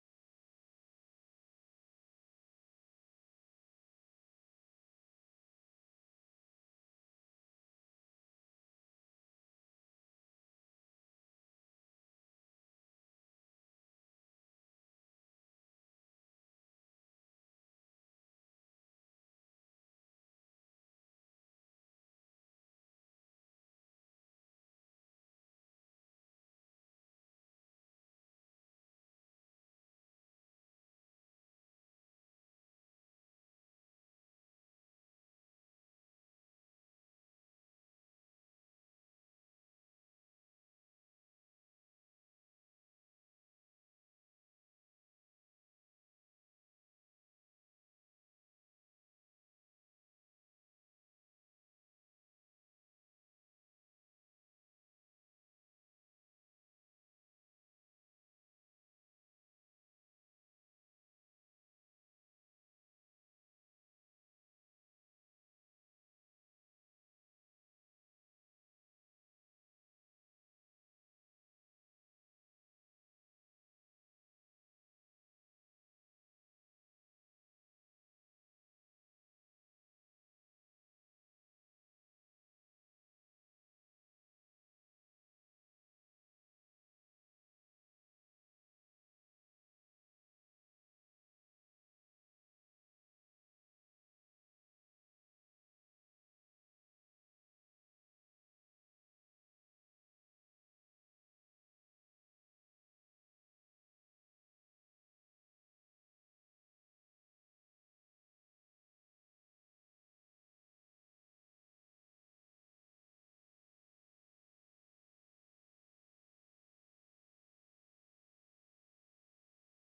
AMB_LVL1_loop.ogg